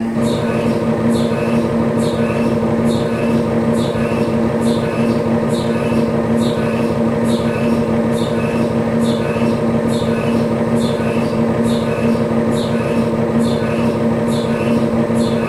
На этой странице представлены звуки работы аппарата МРТ – от ритмичных постукиваний до гудения разной интенсивности.
Шум при сканировании тела на МРТ